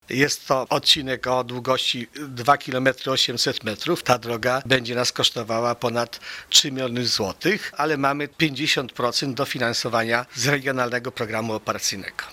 Środki na ten cel samorząd pozyskał z Regionalnego Programu Operacyjnego Województwa Lubelskiego – przypomina zastępca wójta Gminy Łuków Wiktor Osik: